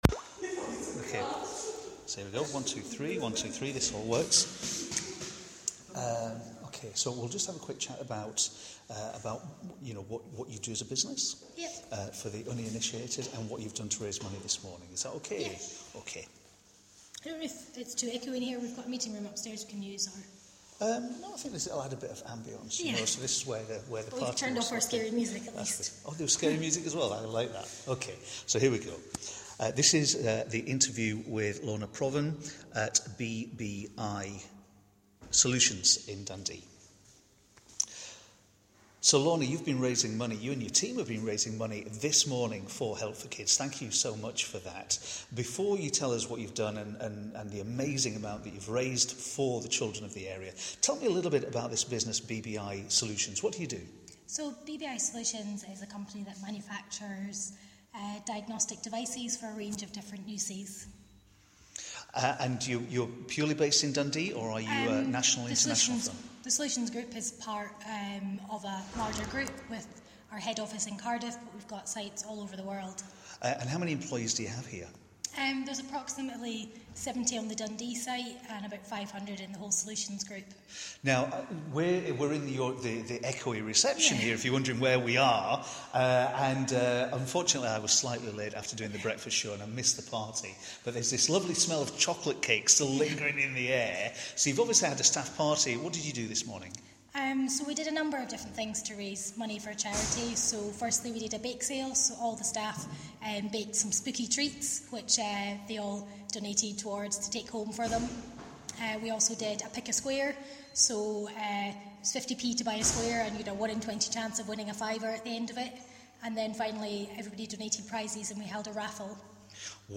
BBI interview